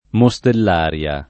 vai all'elenco alfabetico delle voci ingrandisci il carattere 100% rimpicciolisci il carattere stampa invia tramite posta elettronica codividi su Facebook Mostellaria [lat. mo S tell # r L a ] tit. f. — commedia di Plauto